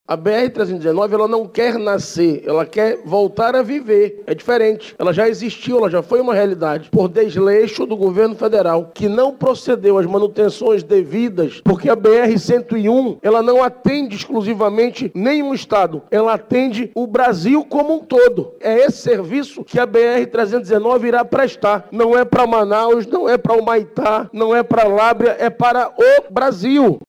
Durante a sessão, o presidente da Casa Legislativa, vereador David Reis, do Avante, ressaltou que a reestruturação da BR-319 não vai atender apenas às demandas da capital amazonense, mas a interesses nacionais.